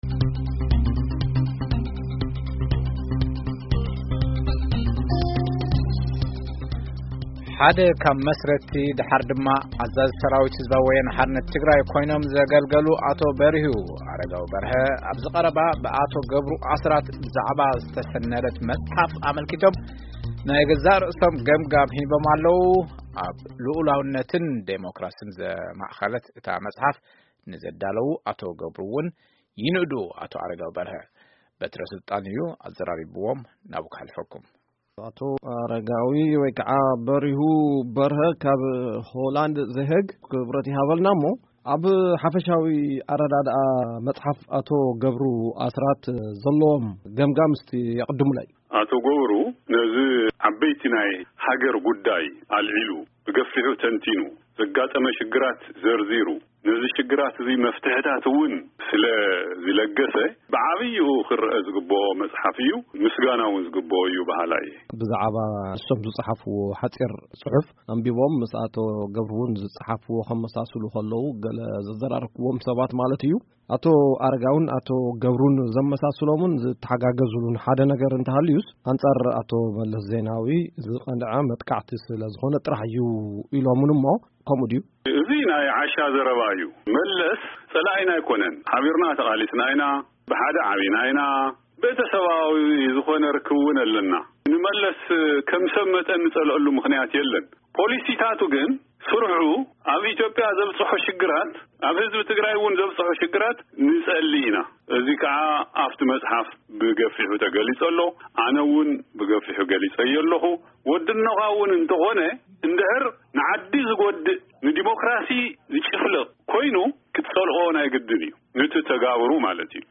1ይ ክፋል ቃለ-መጠይቅ